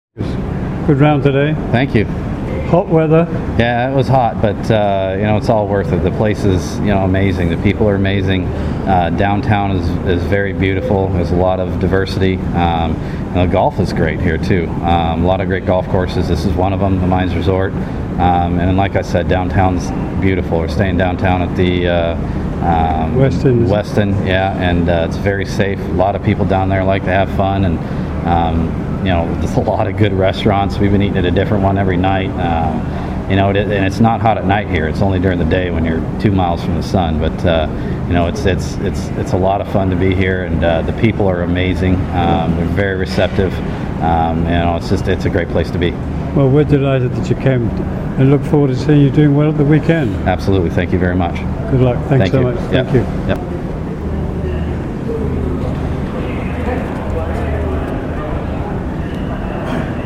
MGTA interviews Robert Garrigus